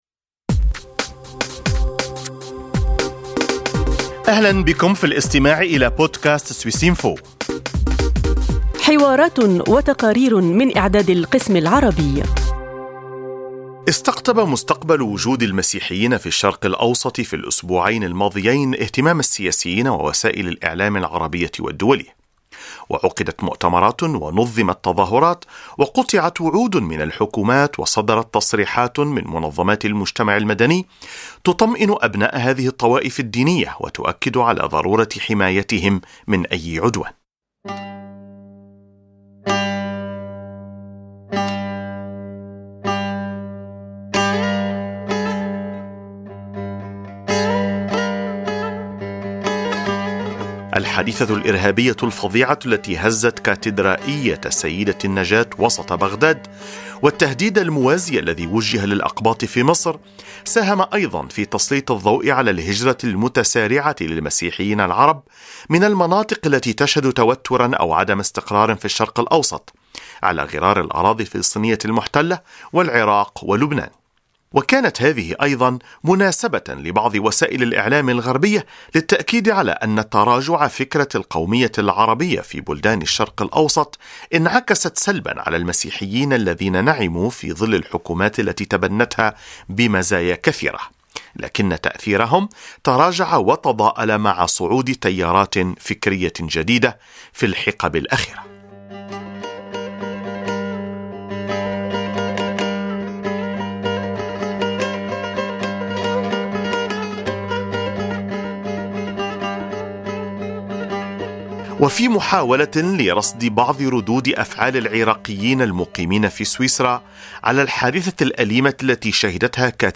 كيف ترددت أصداء حادثة كاتدرائية "سيدة النجاة" المأساوية داخل أوساط الجالية العراقية في سويسرا ولدى المسيحيين بوجه خاص؟ سيدة عراقية مقيمة في الكنفدرالية منذ عام 1988 تجيب عن هذا التساؤل وترفض الإستسلام للإحباط.